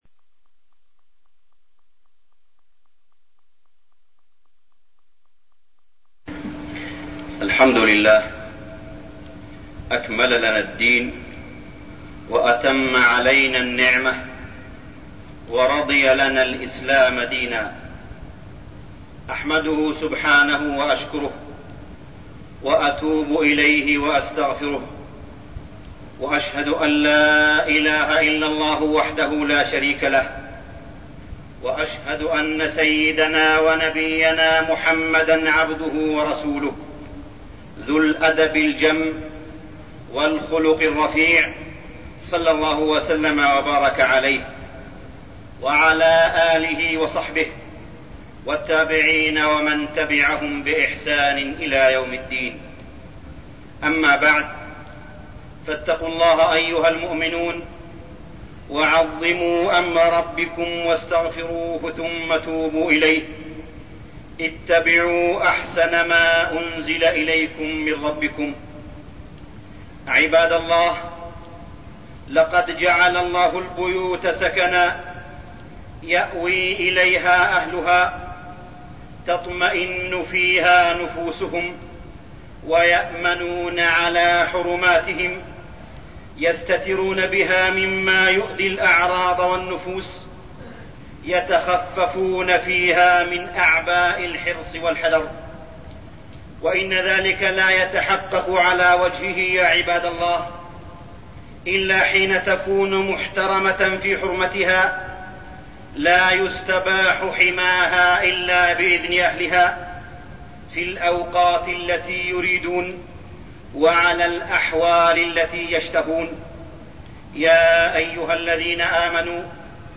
تاريخ النشر ٢١ جمادى الأولى ١٤٠٩ هـ المكان: المسجد الحرام الشيخ: معالي الشيخ أ.د. صالح بن عبدالله بن حميد معالي الشيخ أ.د. صالح بن عبدالله بن حميد الإستئذان